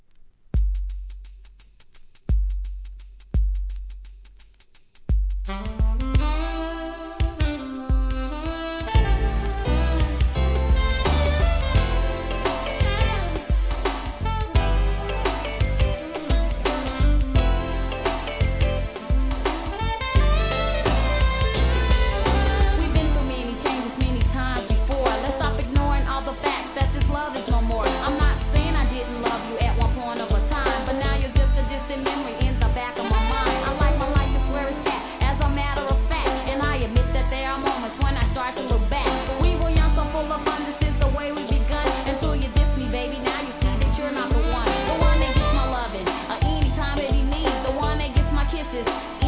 HIP HOP/R&B
SAXイントロの激メロ〜・フィメールRAP!!